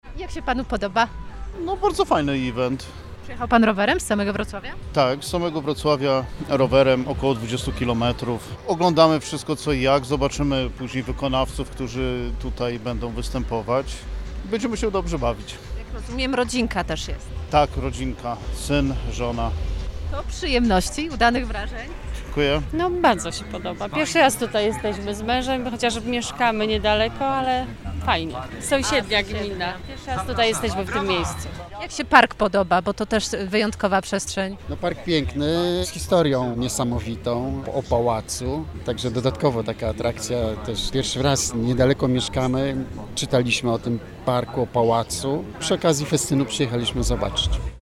O wrażenia zapytaliśmy uczestników.
Sonda-mieszkancy-wrazenia-z-Dni-Gminy.mp3